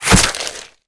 flesh2.ogg